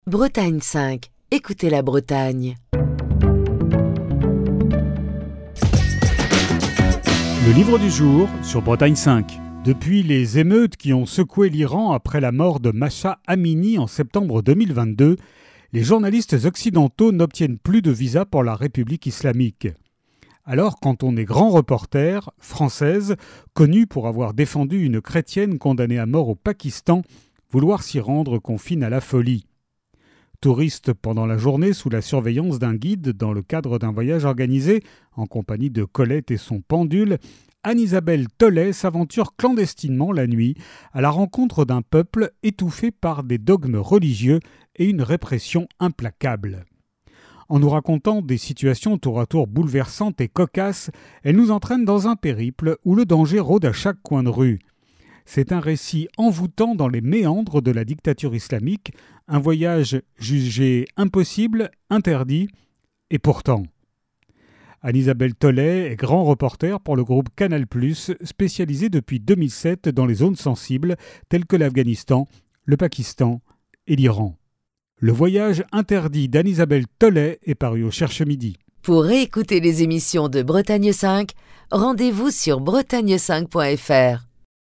Chronique du 2 octobre 2024.